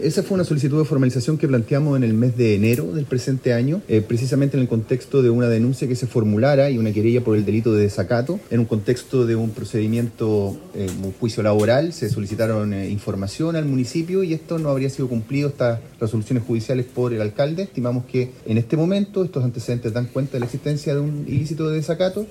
Debido a todo esto se refirió el fiscal regional de La Araucanía, Roberto Garrido, quien detalló que estos antecedentes serían constitutivos de un delito.